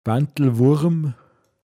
pinzgauer mundart
Bandlwurm, m. Bandwurm